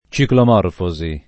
vai all'elenco alfabetico delle voci ingrandisci il carattere 100% rimpicciolisci il carattere stampa invia tramite posta elettronica codividi su Facebook ciclomorfosi [ © iklomorf 0@ i ; alla greca © iklom 0 rfo @ i ] s. f. (zool.)